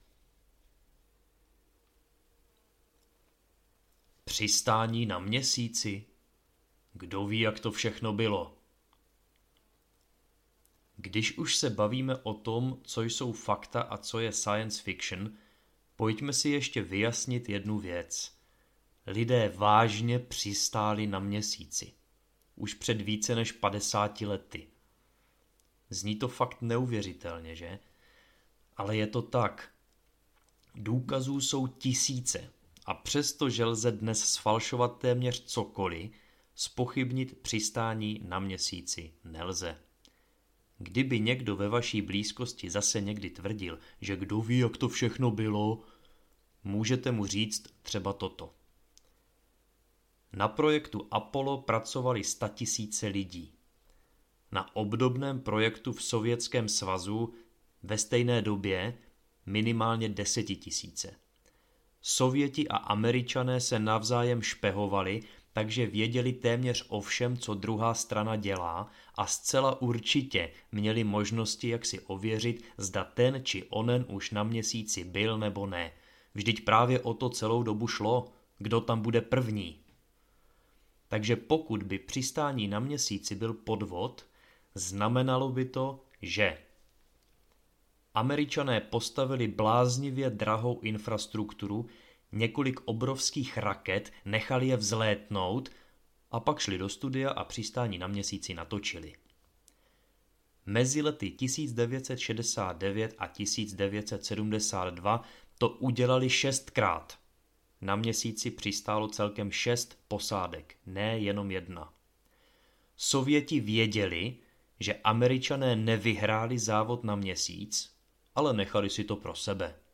Příručka pozemšťana audiokniha
Ukázka z knihy